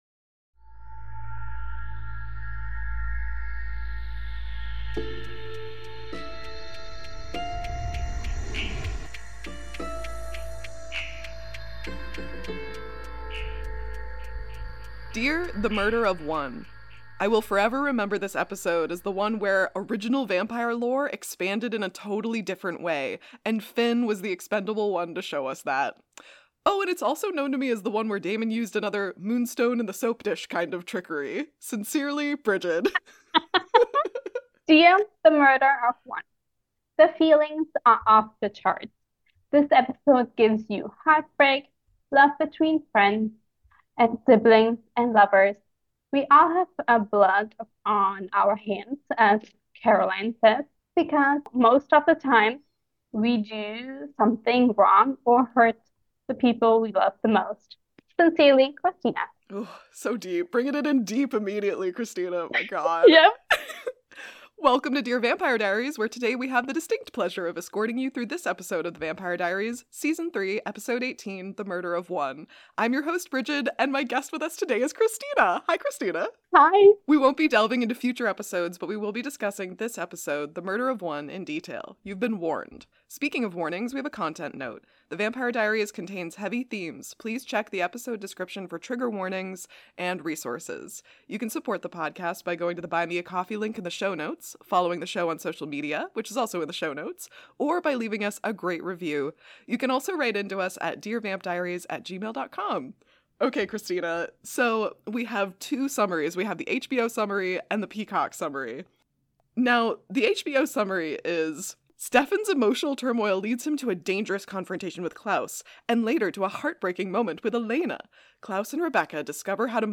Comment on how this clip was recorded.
Please excuse our audio issues. Sorry for the intermittent clicking!&nbsp